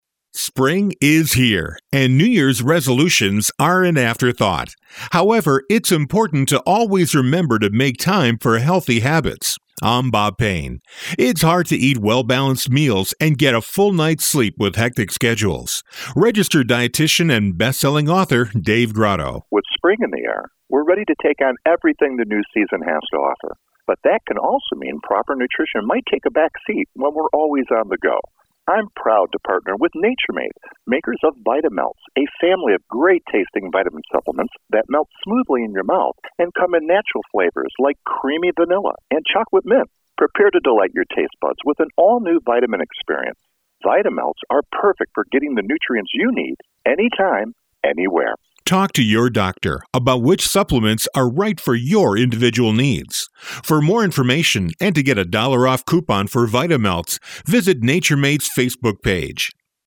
March 28, 2013Posted in: Audio News Release